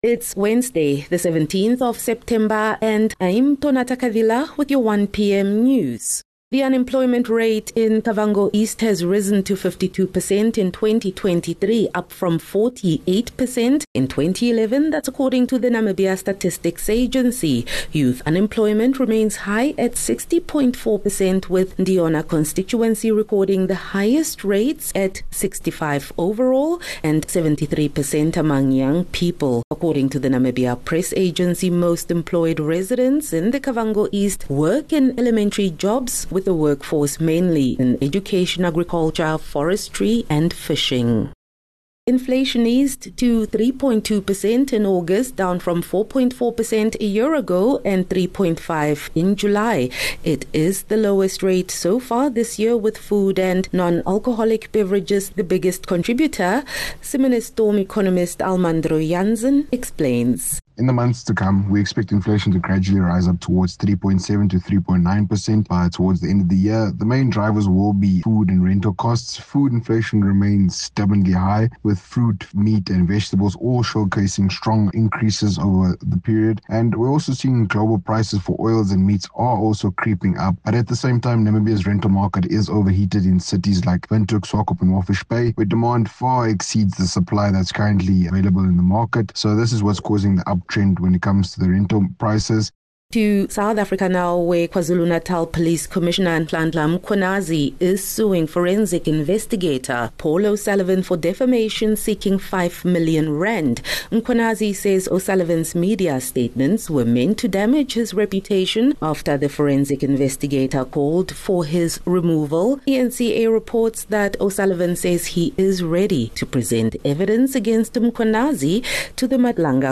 17 Sep 17 September - 1 pm news